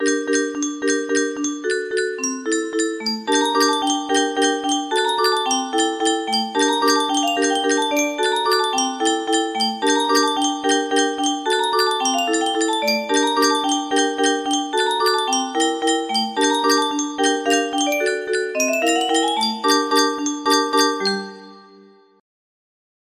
Elunoir's theme music box melody